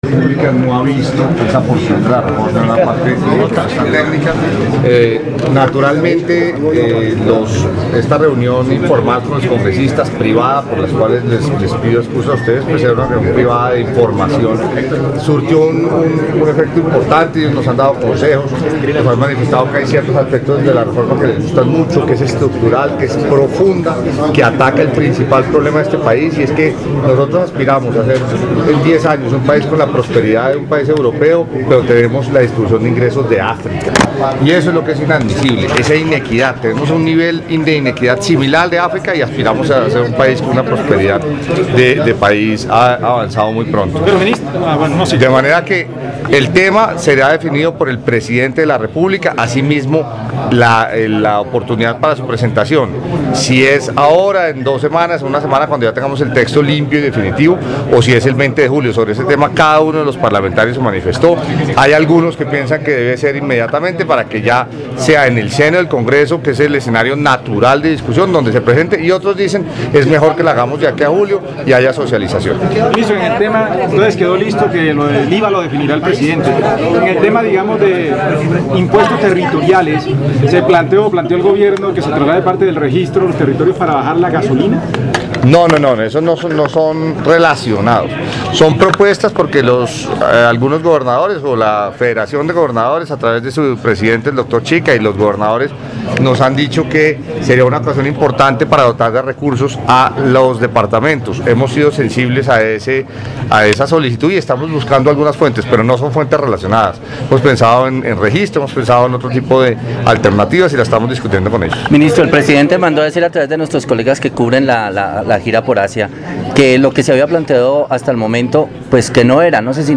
Suscríbete y escucha las noticias jurídicas narradas con IA.